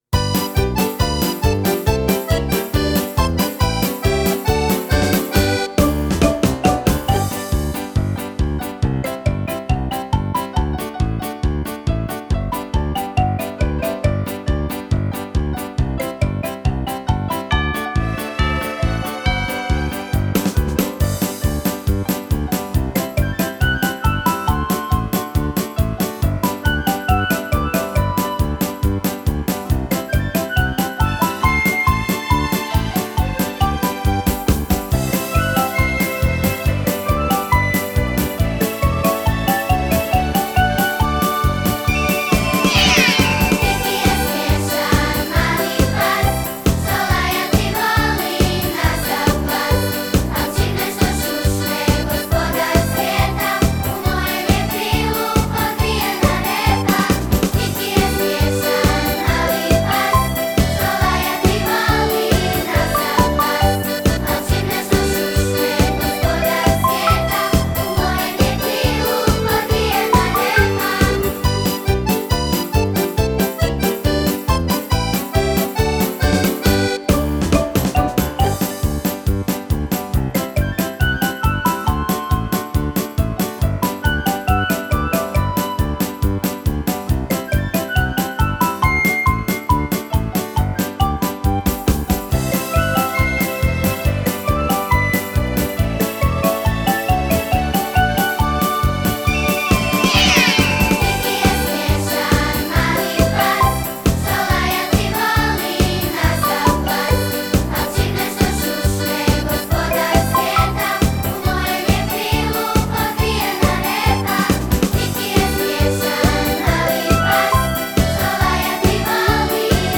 Композиције, извођачи и аутори по редослиједу извођења Ђурђевданског фестивала Бања Лука 2018.
Извођачи: Трио “Генијалке”
02Gospodar-svijeta_matrica.mp3